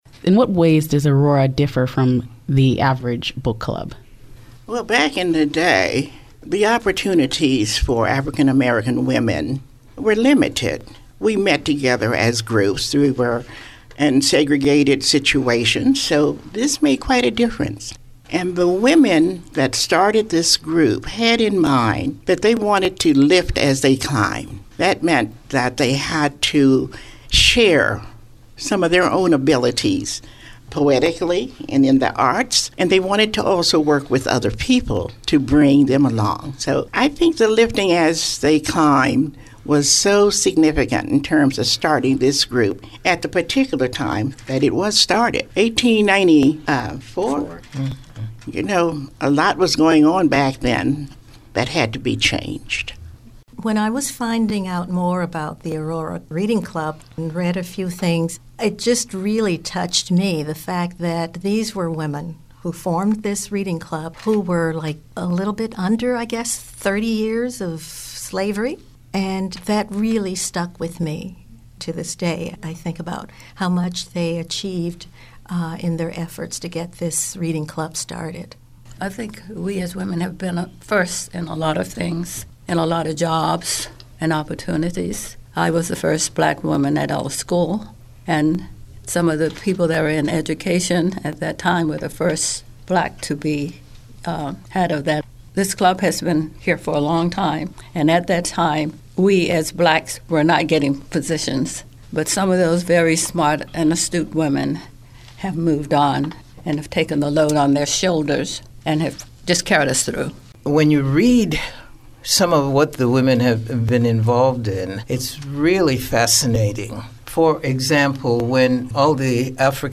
The tracks below were collected from Aurora’s luncheon on April 22nd, 2019.